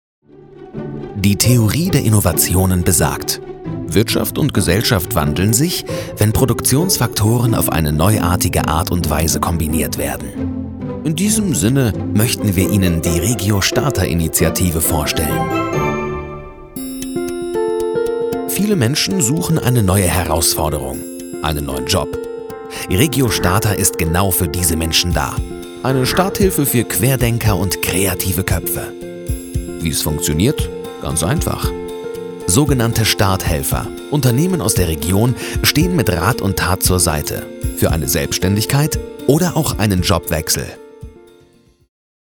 Wandelbare, freundliche und angenehme Männerstimme mittleren Alters.
Sprechprobe: Industrie (Muttersprache):